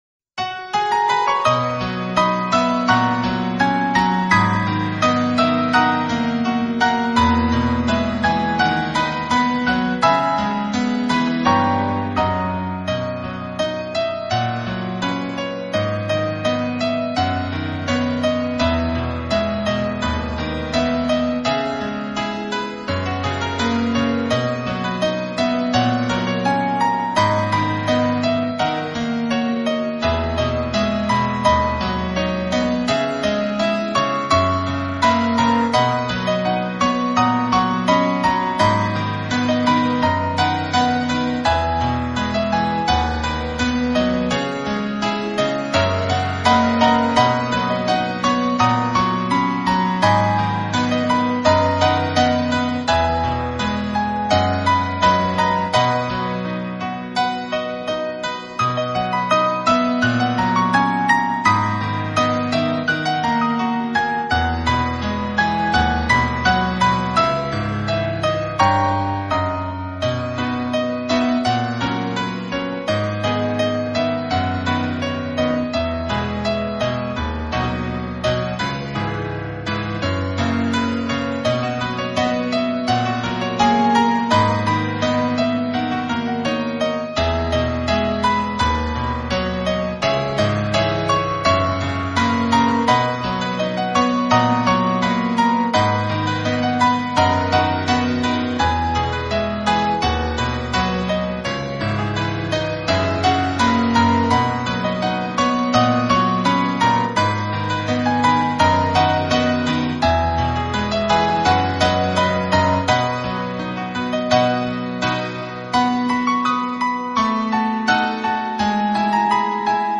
类型：钢琴演奏